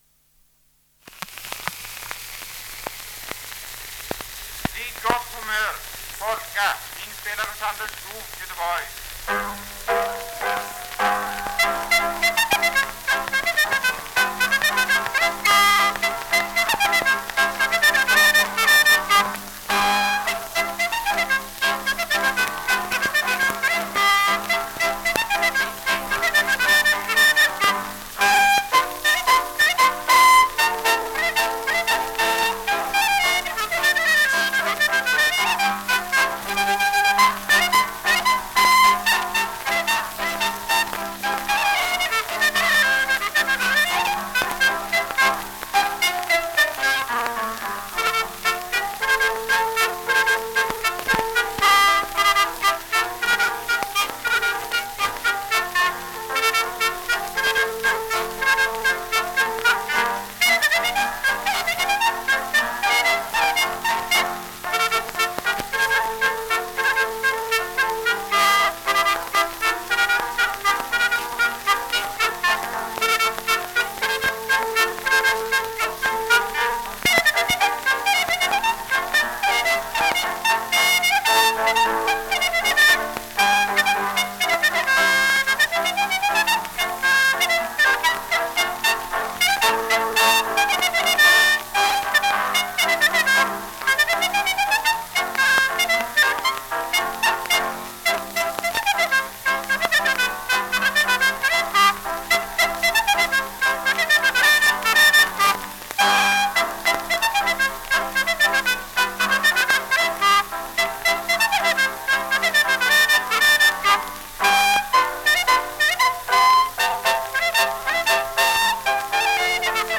Vid gott humör - polka
Ljudinspelningar från omkring 1900